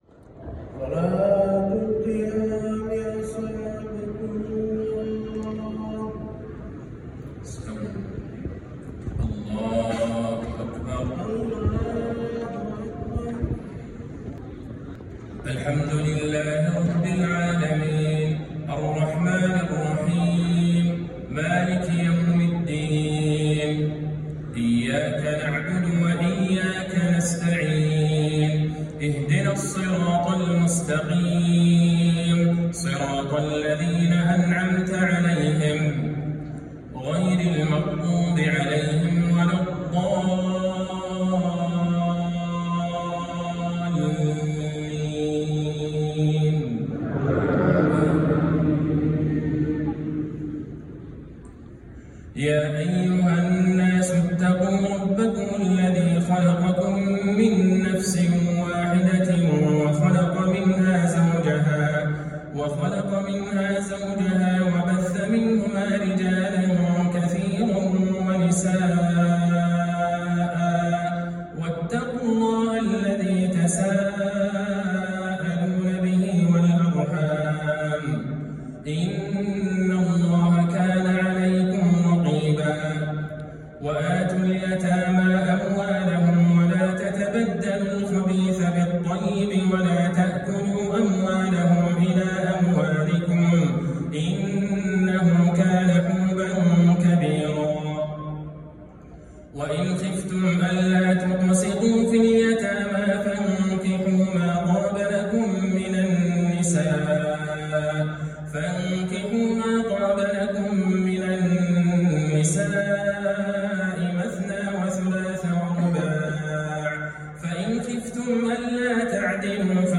تلاوة جميلة وختام مؤثر من سورة النساء للشيخ د.عبدالله البعيجان ليلة ٦ رمضان ١٤٤٣هـ (تسجيل من المسجد)